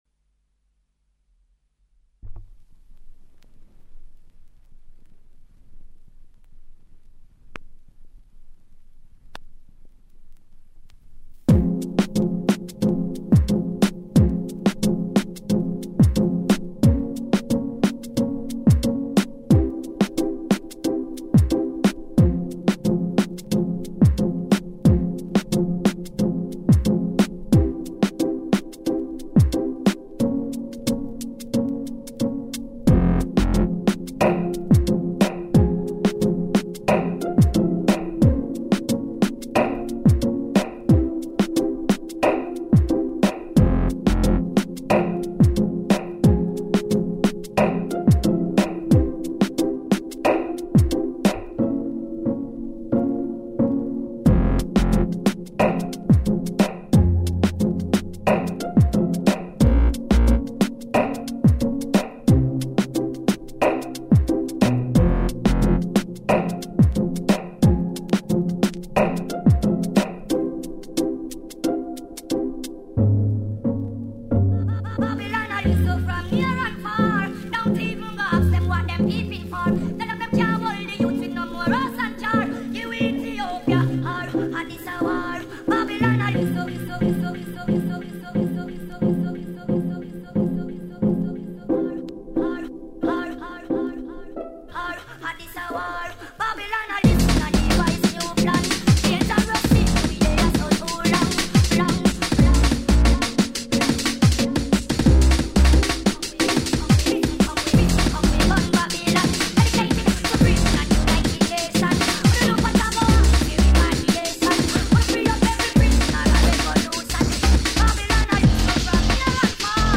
GENRE : Son de teuf!!.
UN MIX